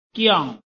臺灣客語拼音學習網-客語聽讀拼-海陸腔-鼻尾韻
拼音查詢：【海陸腔】giong ~請點選不同聲調拼音聽聽看!(例字漢字部分屬參考性質)